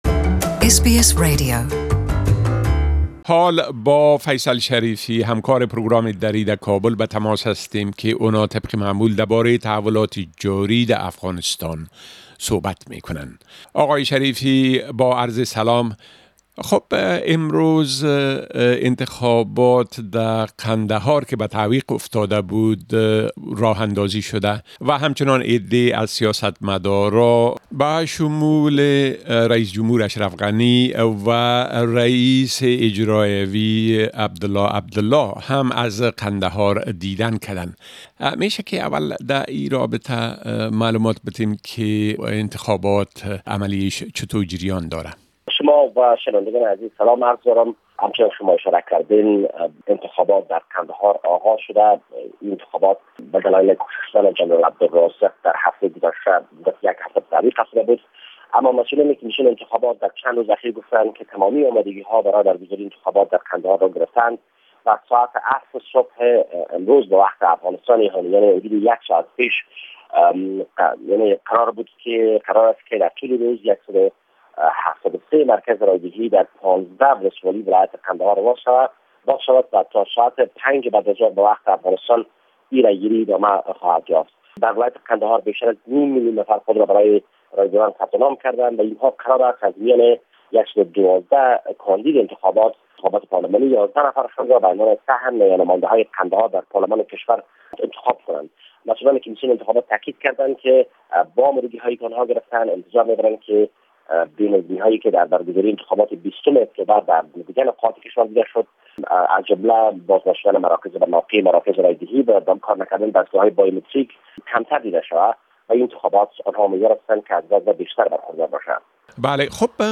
A report from our correspondent in Afghanistan